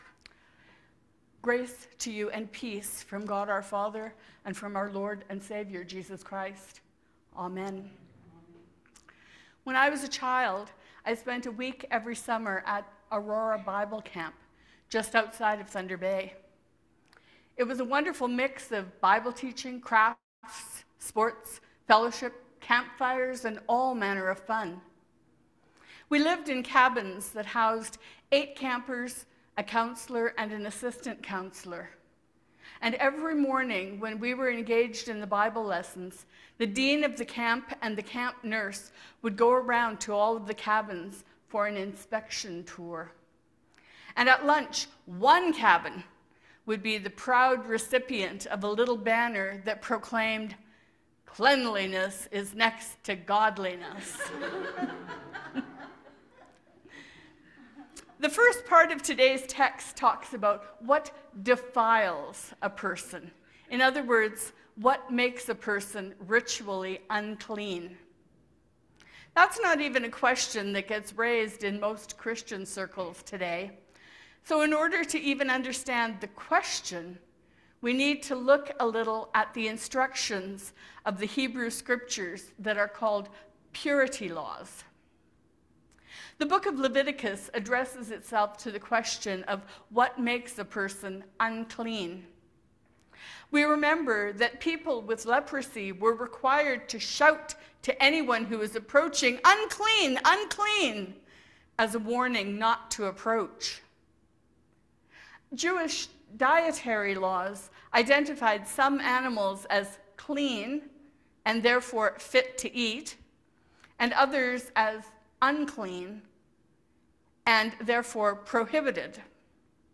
Sermons | St. George's Anglican Church